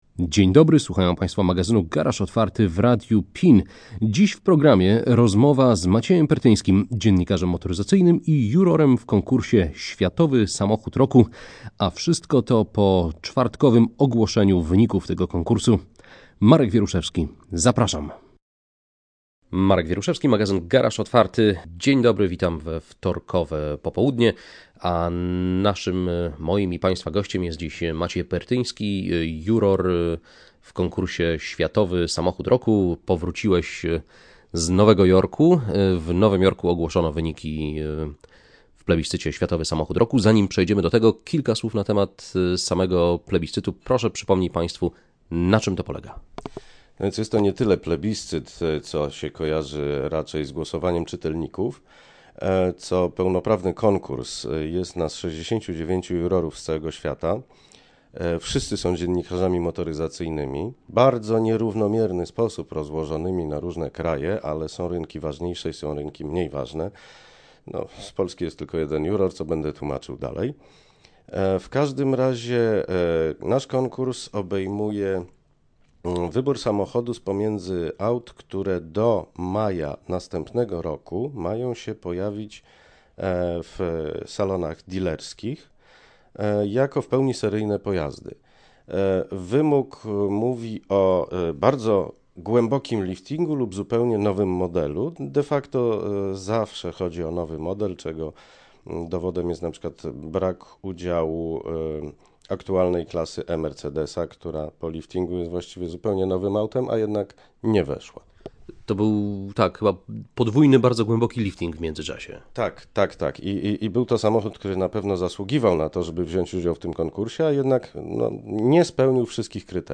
Rozmowa o finale Konkursu z 22.04.2014 w Radio PIN